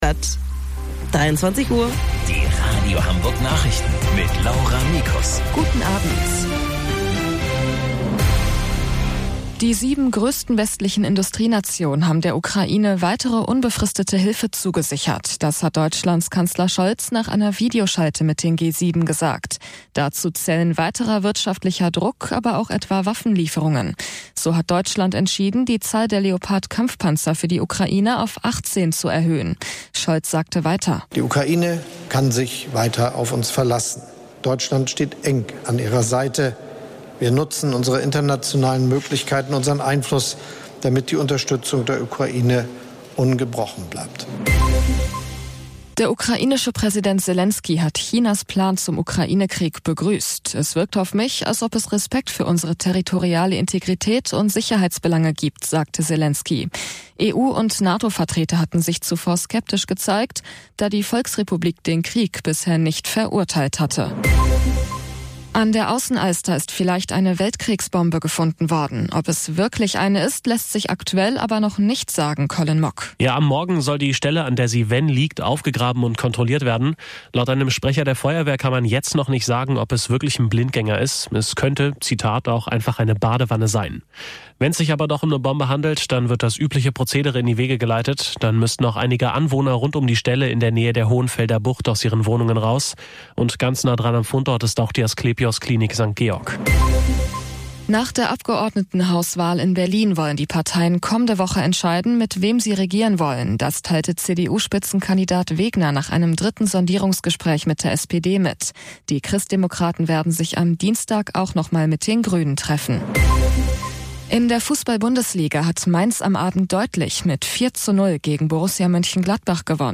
Radio Hamburg Nachrichten vom 04.10.2022 um 04 Uhr - 04.10.2022